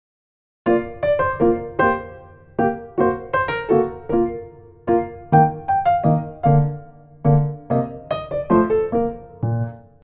7級B/変ロ長調３拍子
２ 応用編（ステキ）
変奏２.mp3